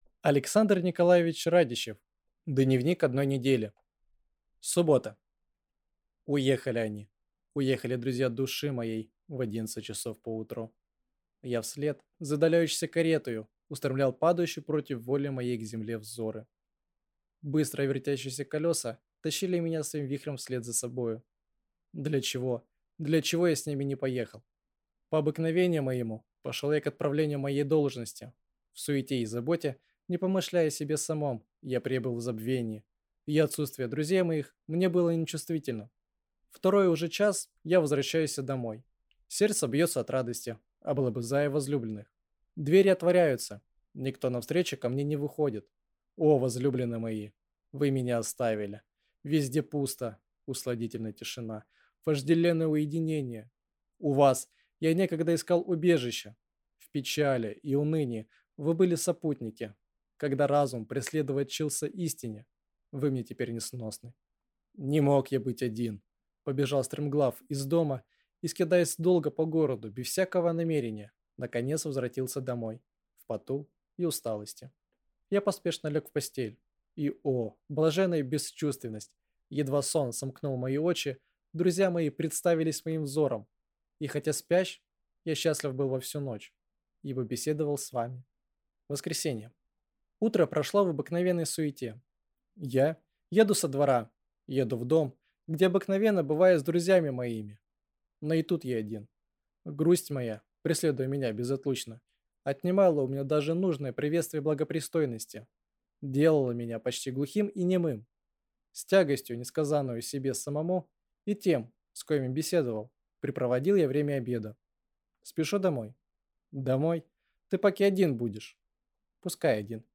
Аудиокнига Дневник одной недели | Библиотека аудиокниг